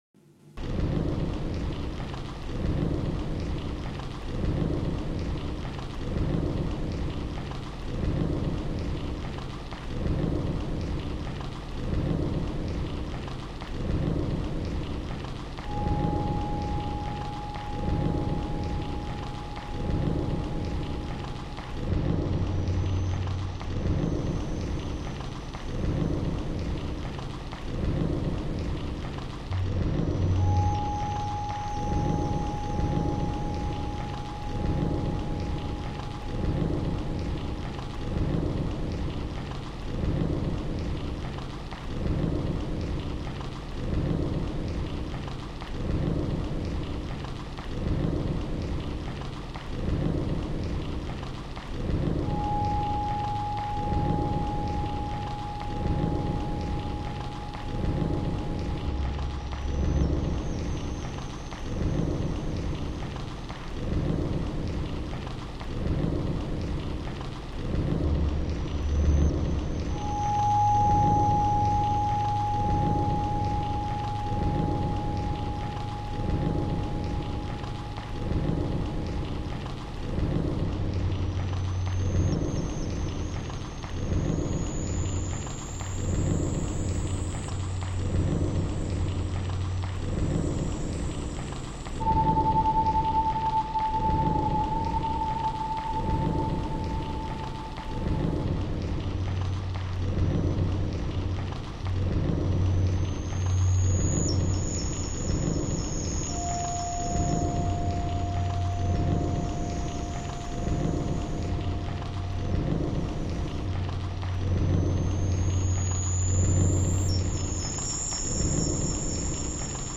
Seema Malaka Meditation Centre